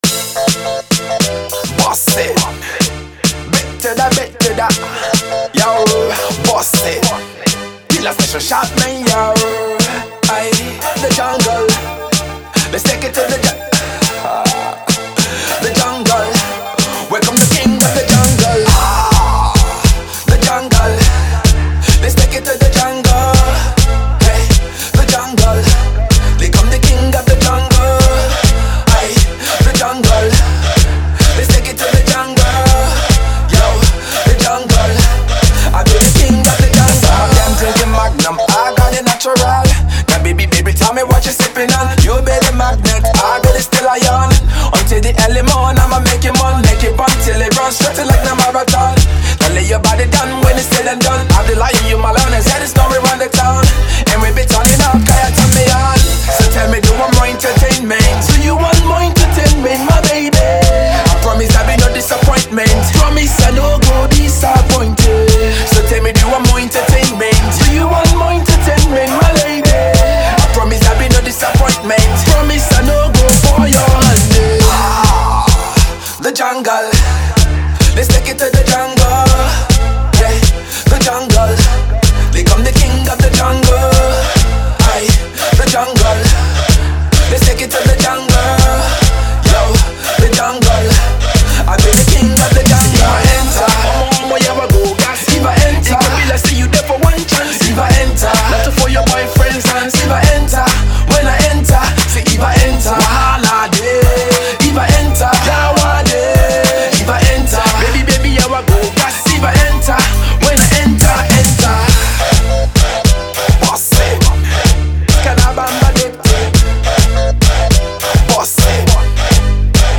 Pop track